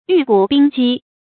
玉骨冰肌 注音： ㄧㄩˋ ㄍㄨˇ ㄅㄧㄥ ㄐㄧ 讀音讀法： 意思解釋： 形容女子苗條的身段和潔白光潤的肌膚。